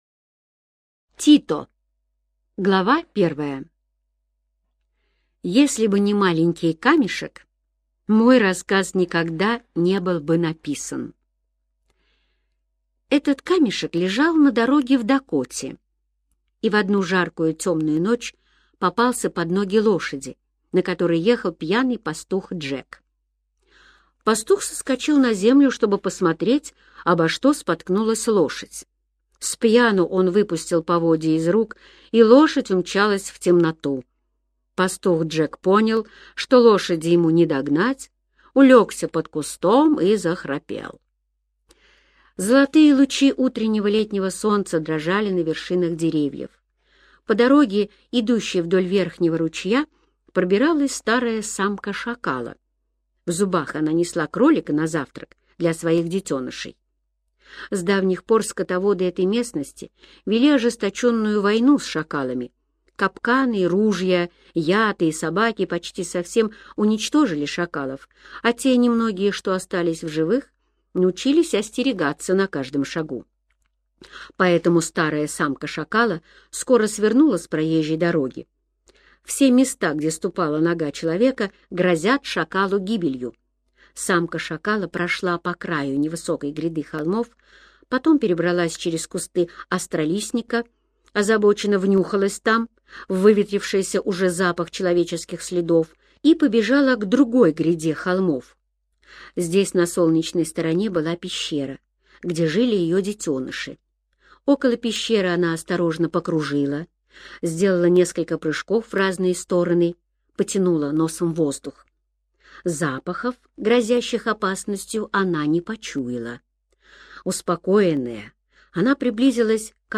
Тито - аудио рассказ Эрнеста Сетона-Томпсона - слушать онлайн